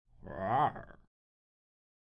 Tigre Téléchargement d'Effet Sonore
Tigre Bouton sonore